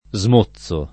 smozzo [ @ m 1ZZ o ]